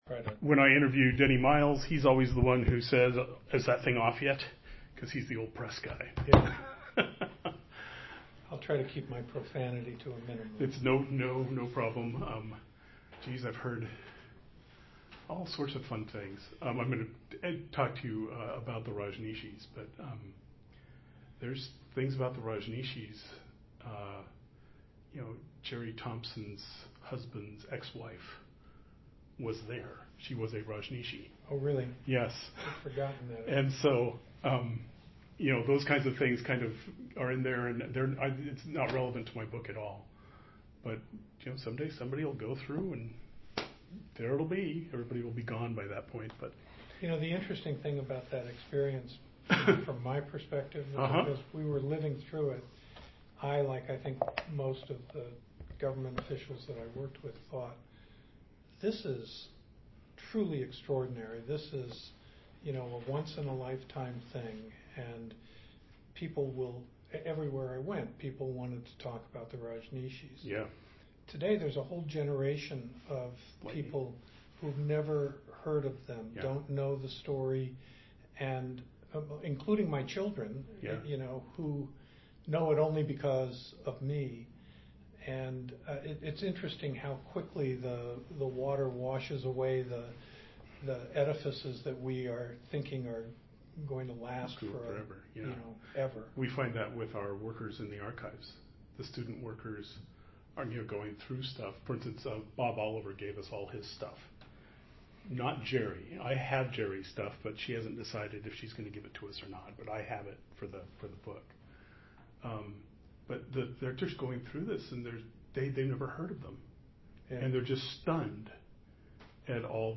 af6bf3b1f59463eca6dd1c838ee733dcfae3bc81.mp3 Title Bill Gary interview on Atiyeh Description An interview of Bill Gary on the topic of Oregon Governor Vic Atiyeh and the Rajneesh Crisis, recorded on June 14, 2016. Gary was a Solicitor General and later, Assistant Attorney General for the State of Oregon during Atiyeh's governorship.